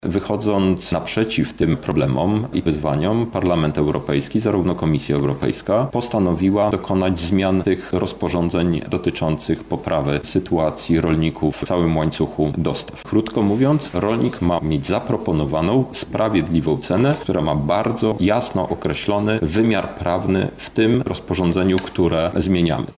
W Lublinie odbyła się konferencja prasowa eurodeputowanego Krzysztofa Hetmana, poświęcona pomocy producentom rolnym.
Krzysztof Hetman– mówi europoseł Krzysztof Hetman